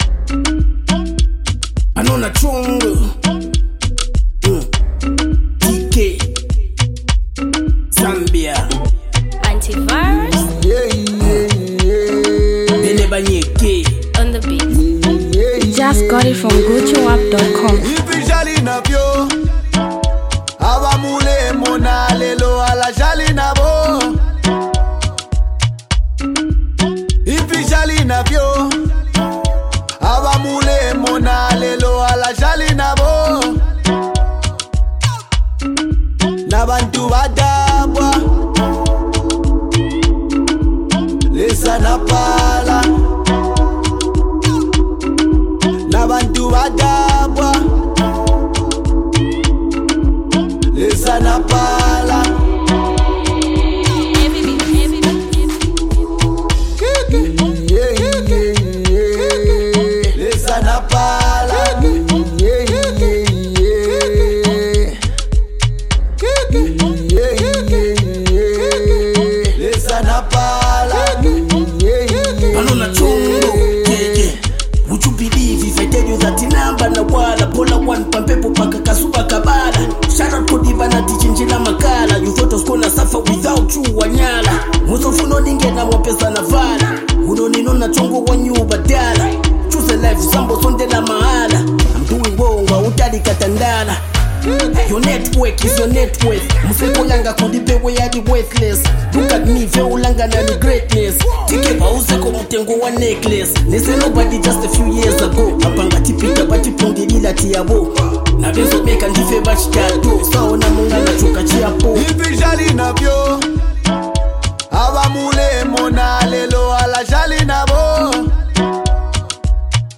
Zambian Mp3 Music
hardcore rapper